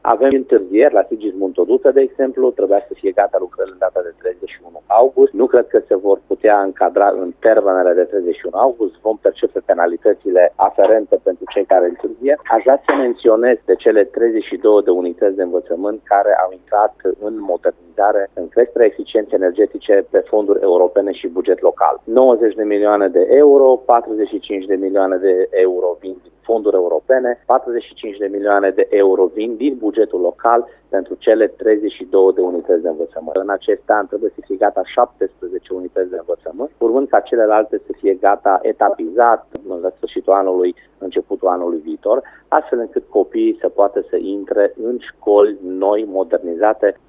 Dacă șantierul școlii Ion Creangă s-a încheiat mai repede, nu același lucru se poate spune despre lucrările de la Colegiul De Muzică „Sigismund Toduță”, spune viceprimarul Dan Tarcea: